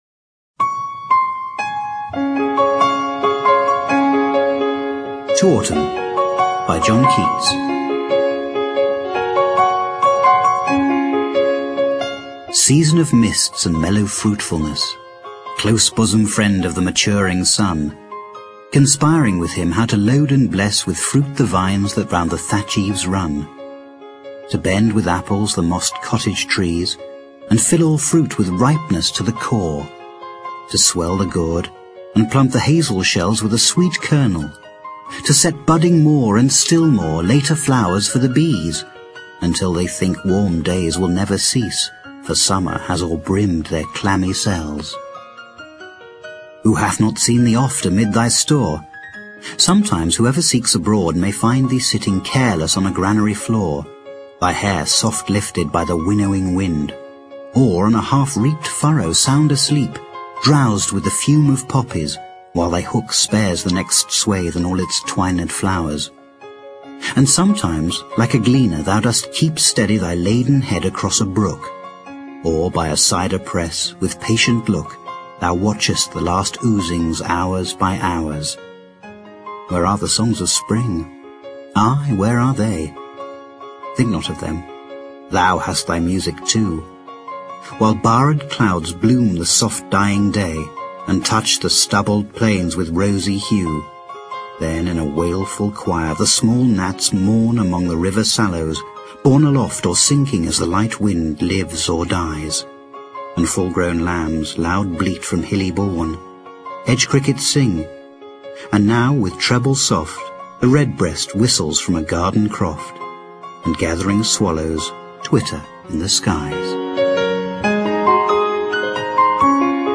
双语有声阅读：秋颂 听力文件下载—在线英语听力室